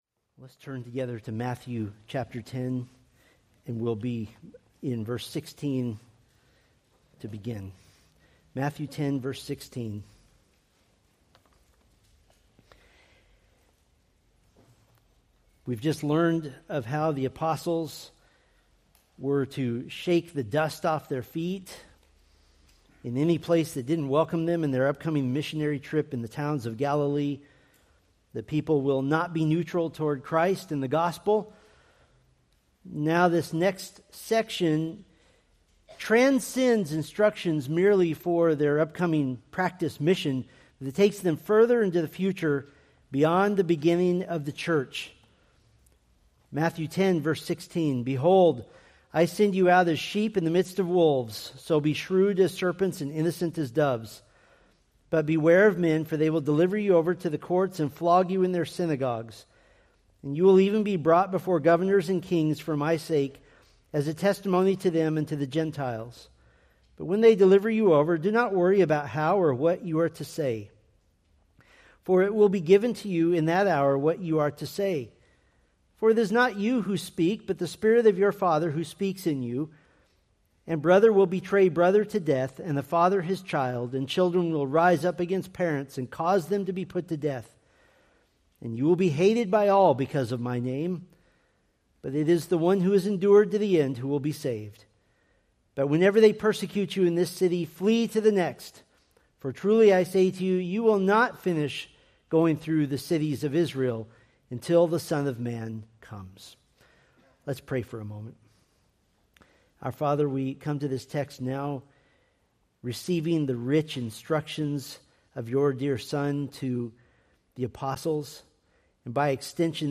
Preached May 4, 2025 from Matthew 10:16-23